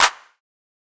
Clap (Low).wav